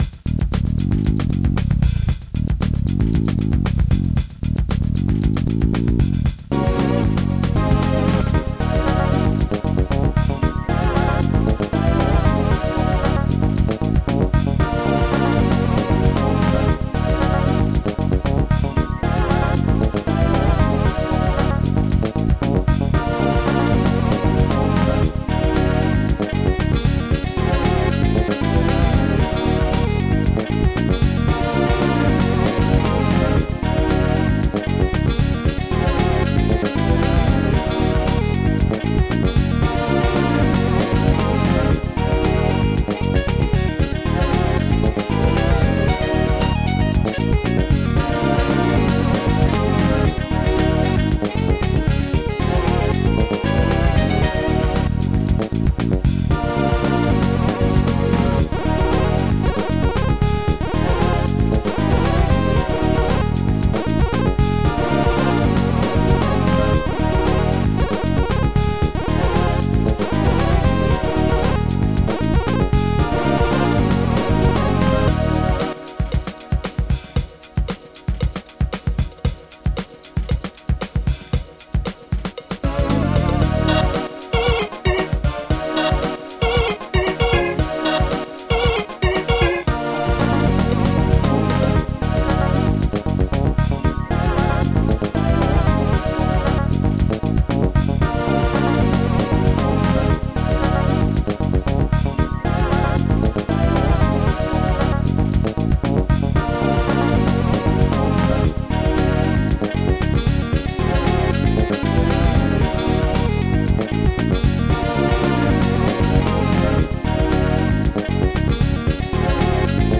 Style: Funk